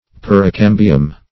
Search Result for " pericambium" : The Collaborative International Dictionary of English v.0.48: Pericambium \Per`i*cam"bi*um\, n. [NL.